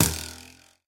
bowhit3.ogg